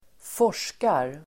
Uttal: [²f'år_s:kar]